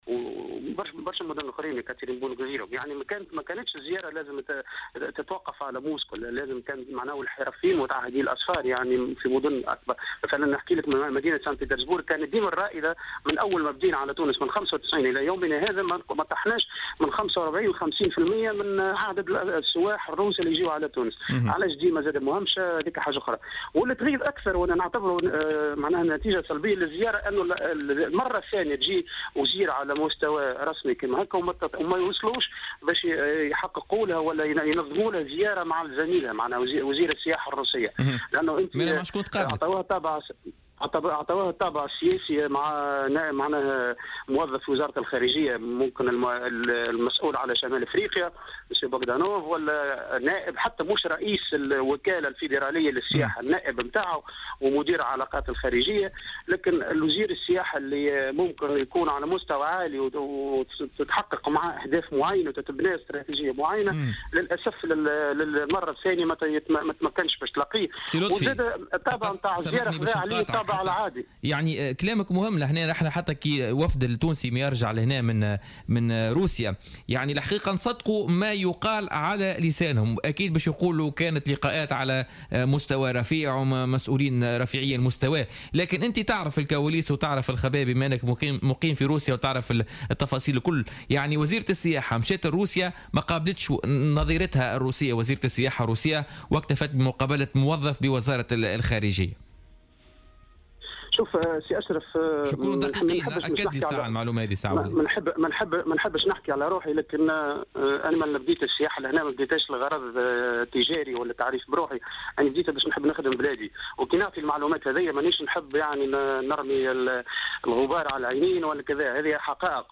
وقال المدير العام لوكالة أسفار في مداخلة هاتفية ببرنامج "بوليتيكا" إن الوزيرة لم تنتهز فرصة تواجدها في روسيا لبحث مشاكل متعهدي الأسفار في علاقة بين السوقين التونسية والروسية في مجال السياحة.